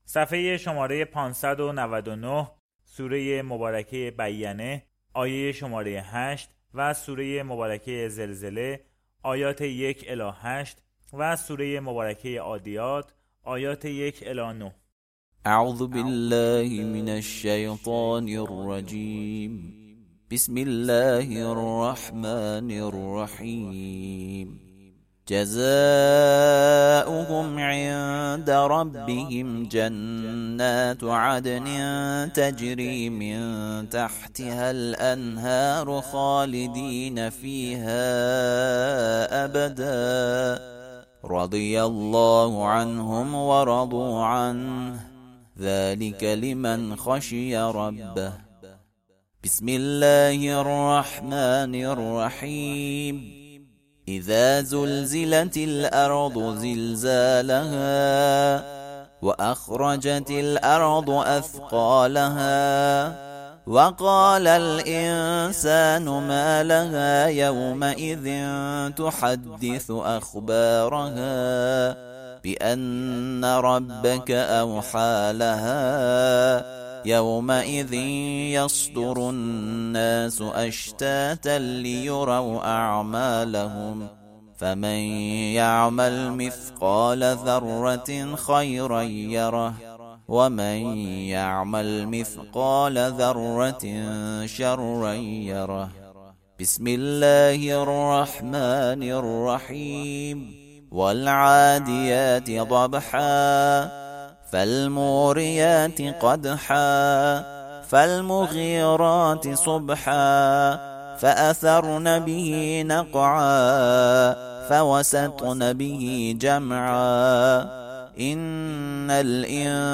ترتیل صفحه ۵۹۹ از سوره بینه، زلزله و عادیات (جزء سی)